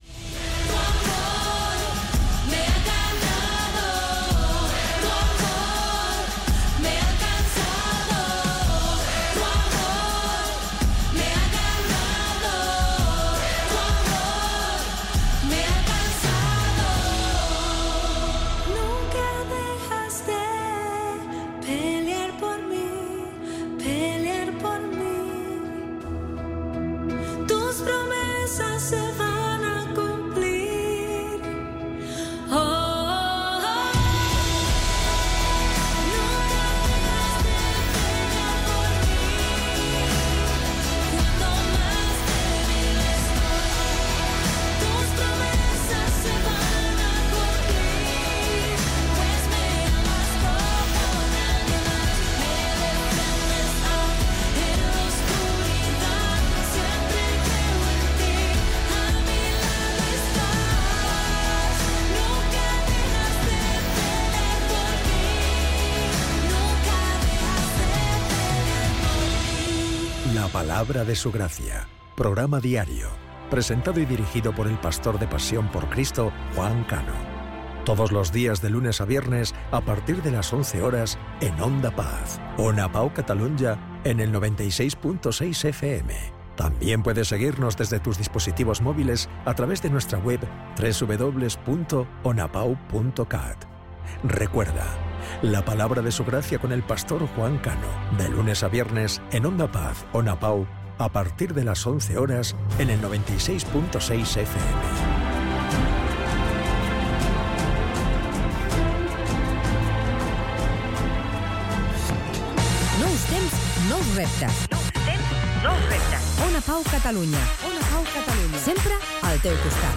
Tema musical ,promoció del programa "La palabra de su gracia", identificació i tema musical
FM